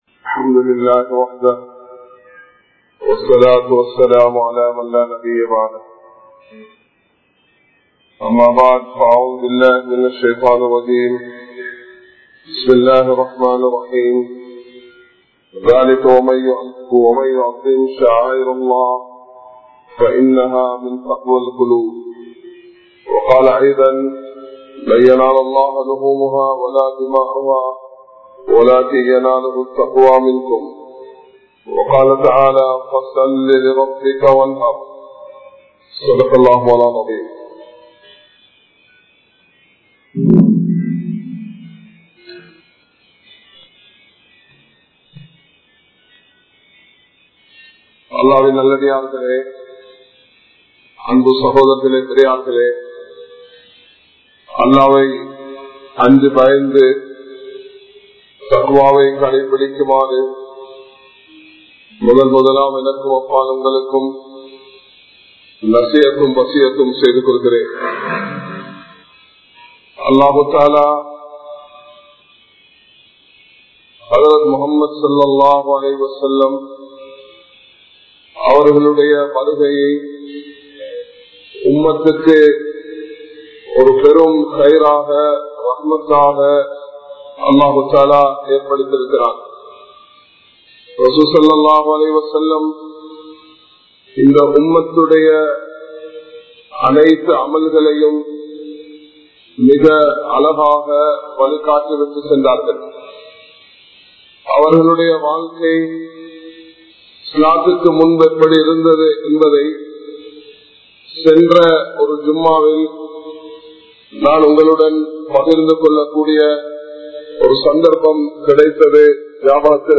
Ottrumaium Ulhiyavum (ஒற்றுமையும் உழ்ஹிய்யாவும்) | Audio Bayans | All Ceylon Muslim Youth Community | Addalaichenai
Dehiwela, Muhideen (Markaz) Jumua Masjith